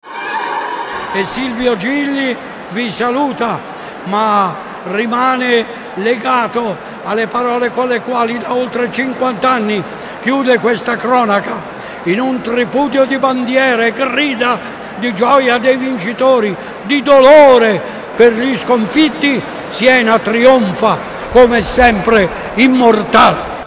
Ecco il saluto di Silvio Gigli a tutti gli amici del Palio di Siena